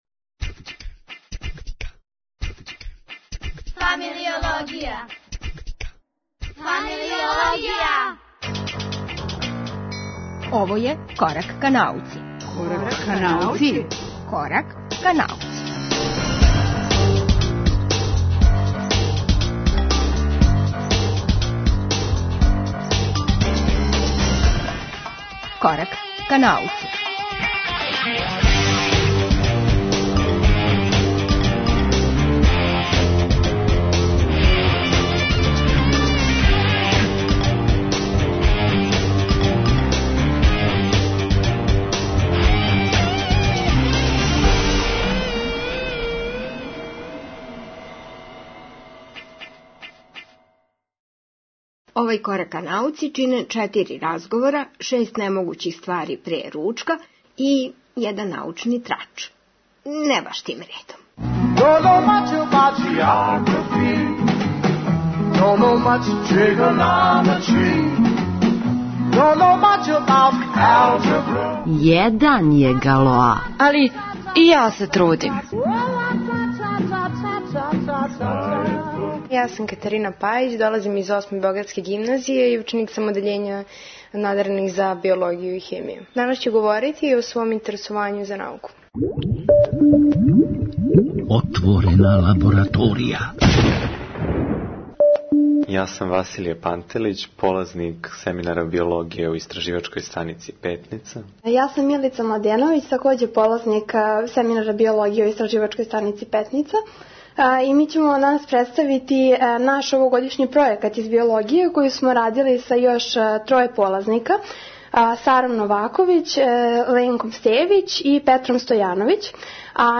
Ову емисију чине четири разговора, један научни трач и шест немогућих ствари пре ручка.